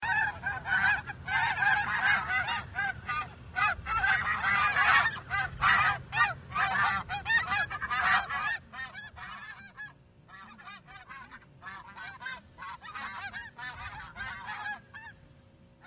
Geese_Honking_(loud).mp3